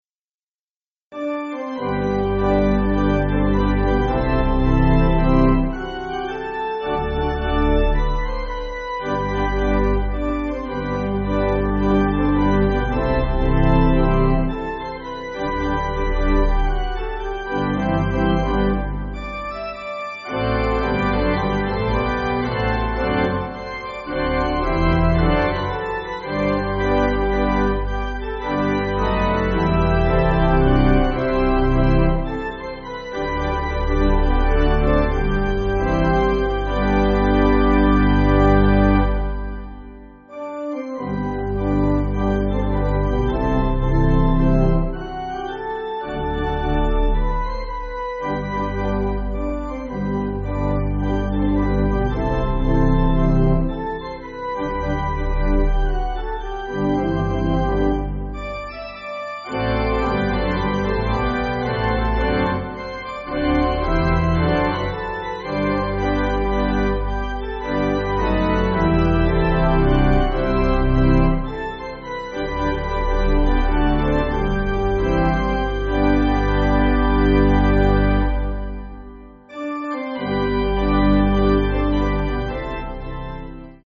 Organ
(CM)   3/G